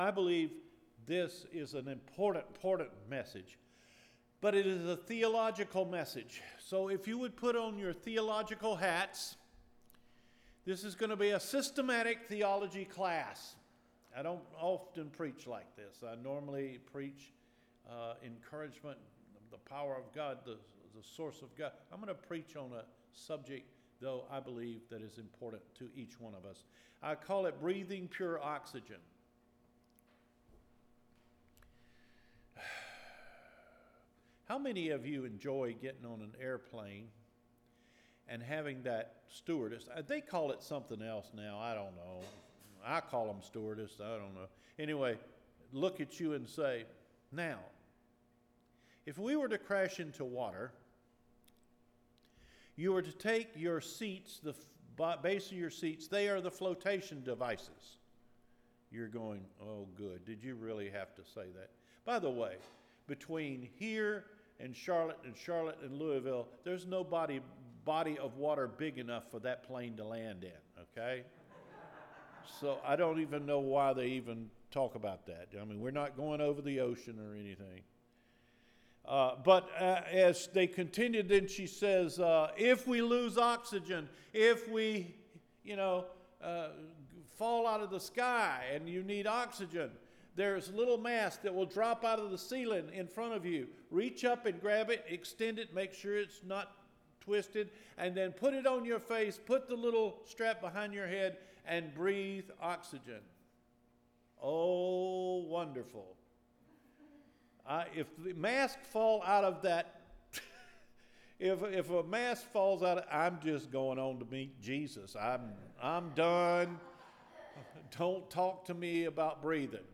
BREATHING PURE OXYGEN – NOVEMBER 8 SERMON